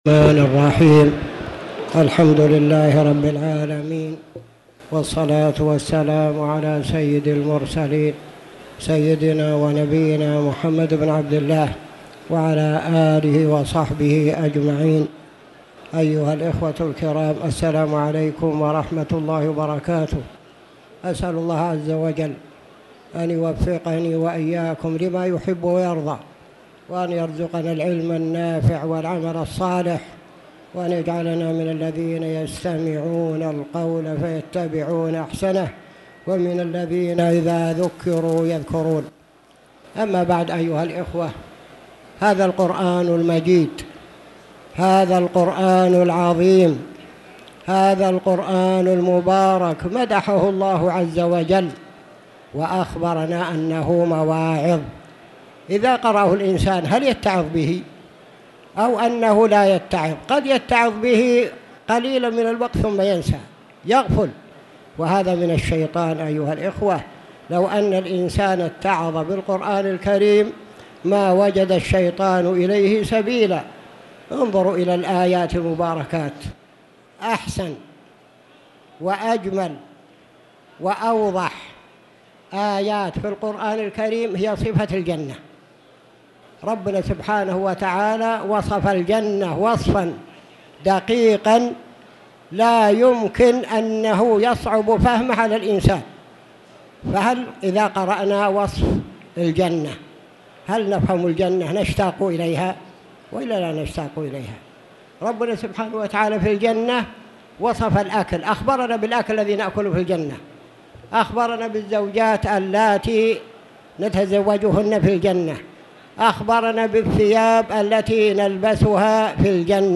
تاريخ النشر ٢٤ رمضان ١٤٣٧ هـ المكان: المسجد الحرام الشيخ